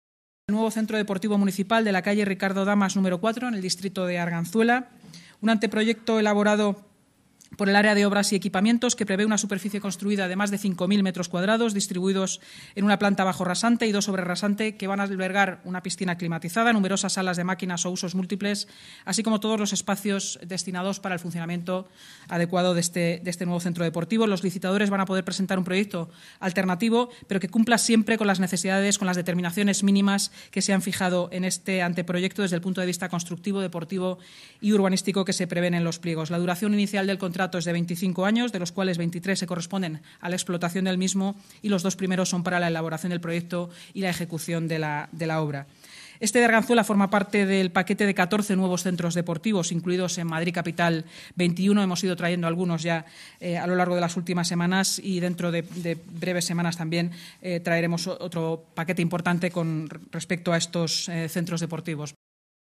Nueva ventana:Declaraciones de Inmaculada Sanz, portavoz municipal y delegada de Seguridad y Emergencias, durante la rueda de prensa posterior a la Junta de Gobierno